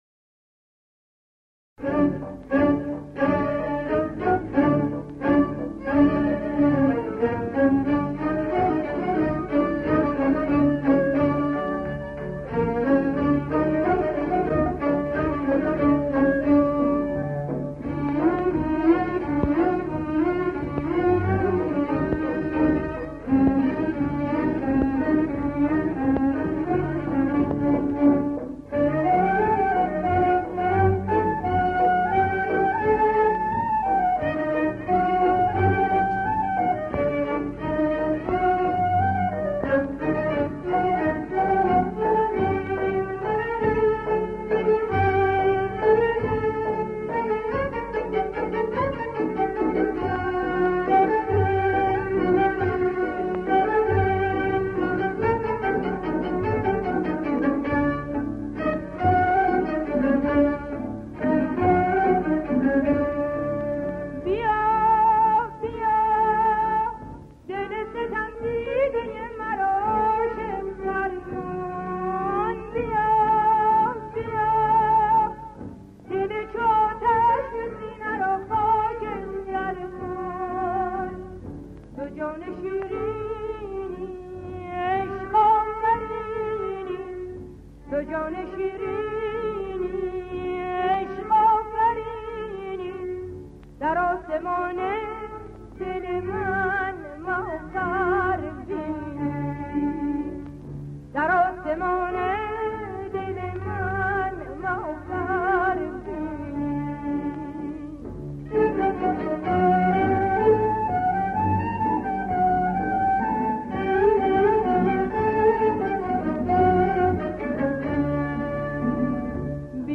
در مقام: چهارگاه